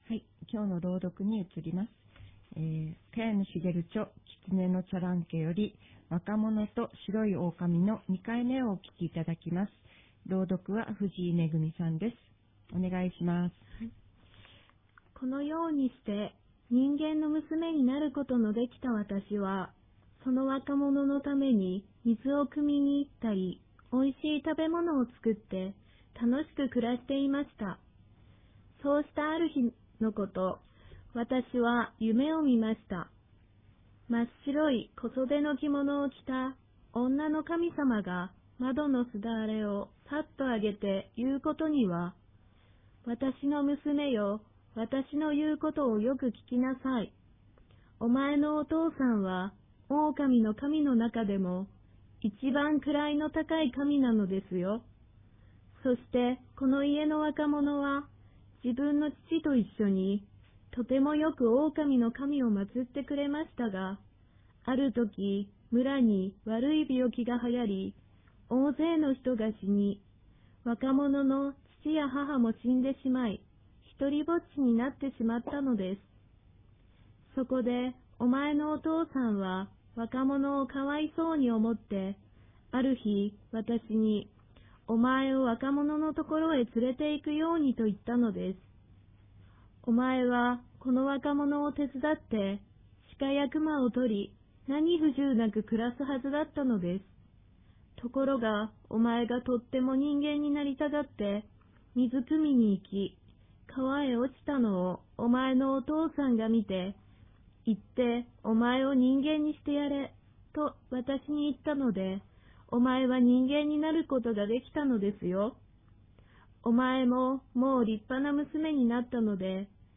■朗読（２３）：「わかものと白いオオカミ」（後半）（萱野茂 著『キツネのチャランケ』（小峰書店）より）